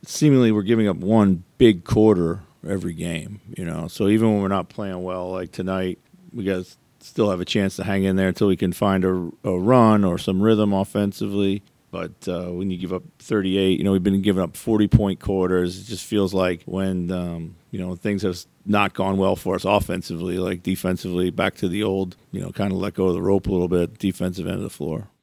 Wolves head coach Chris Finch says they are giving up too many big quarters for their opponents.